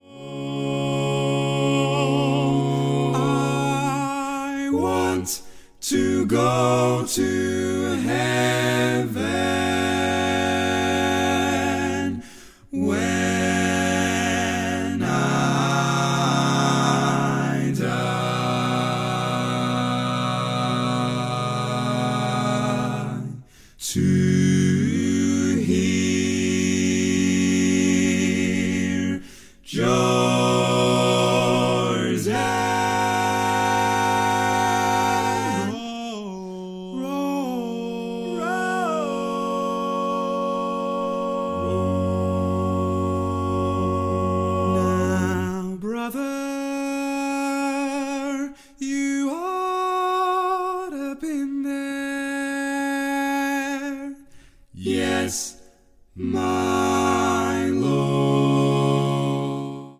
Full mix only
Category: Male